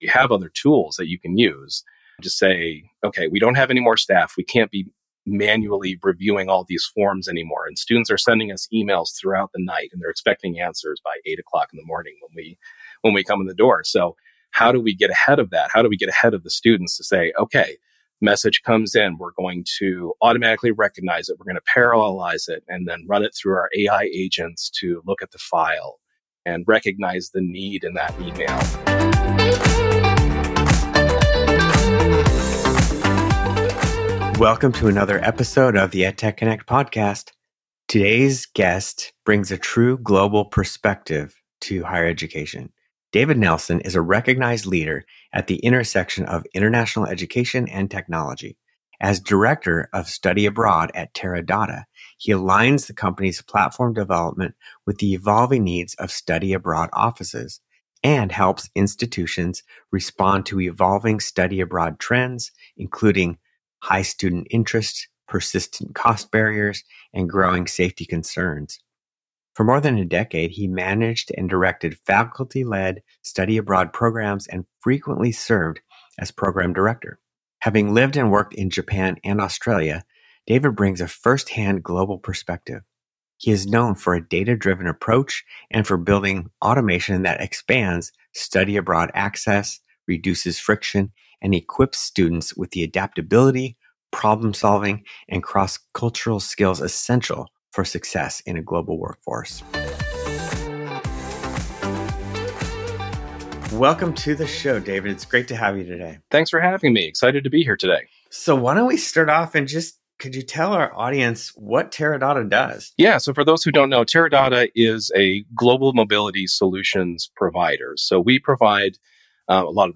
Each episode features interviews with leading experts, educators, technologists and solution providers, who share their insights on how technology can be used to improve student engagement, enhance learning outcomes, and transform the educational experience.